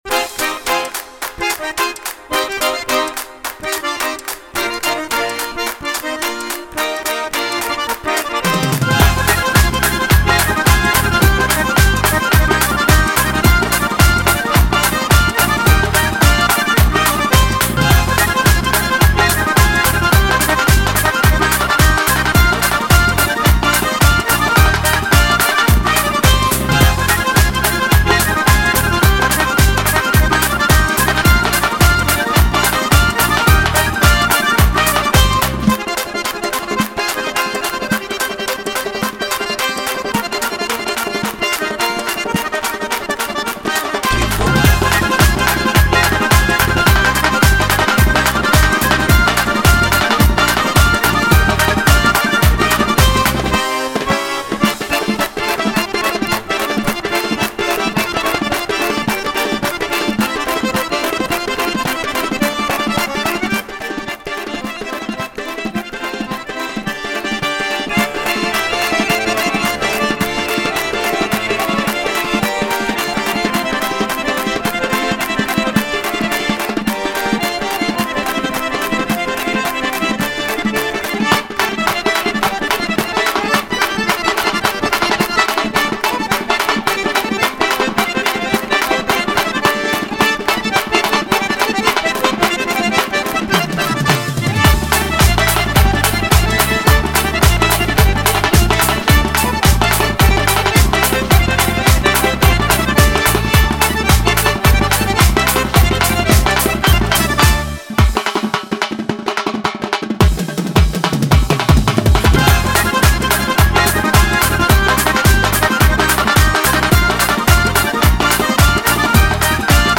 Категория: лезгинки